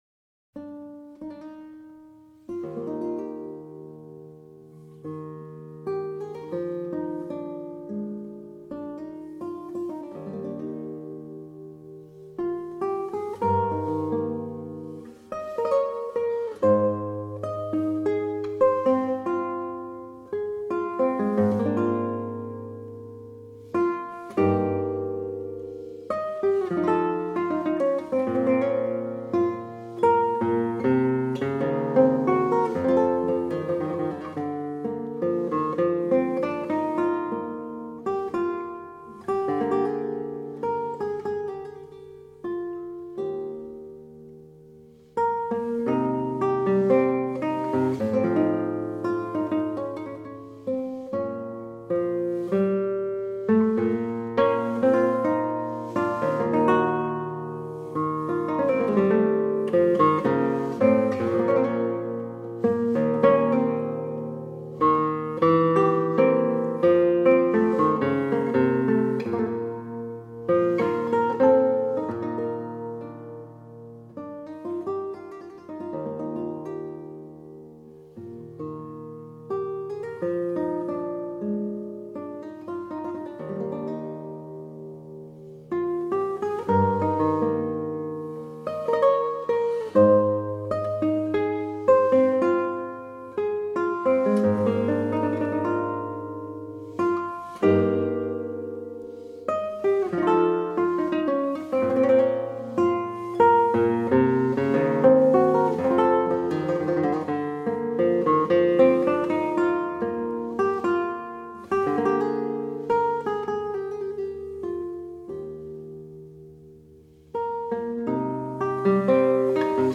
Harpsichord Works in Transcription for Guitar
Ornamental stylings and delivery are superior.”